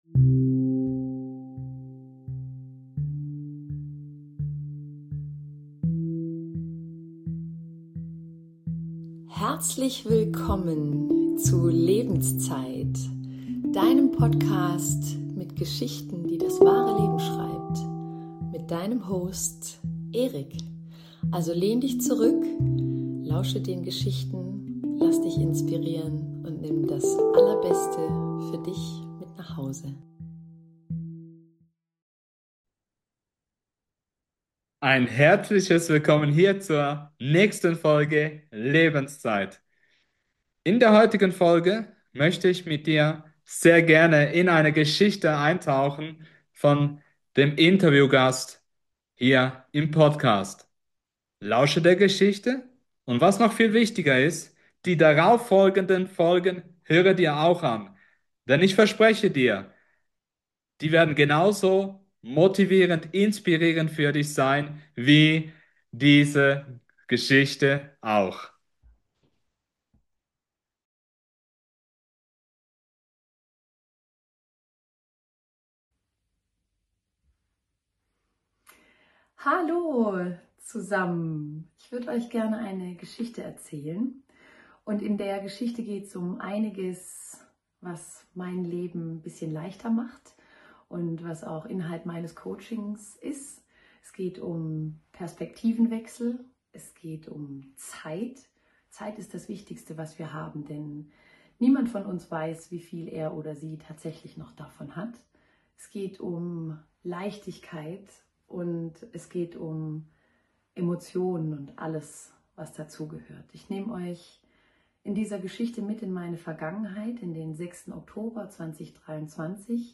Keynote zum Thema Abschied und Verlust